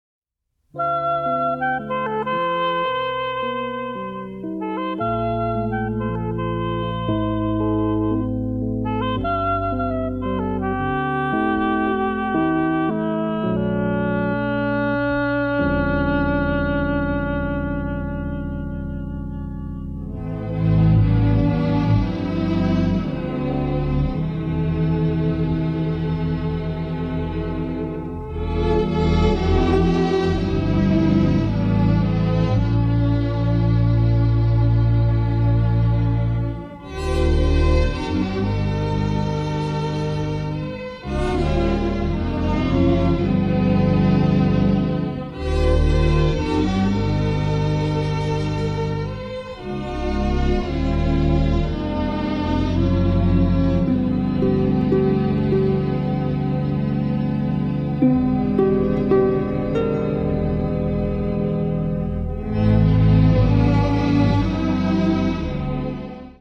combines a full symphony orchestra with custom synthesizers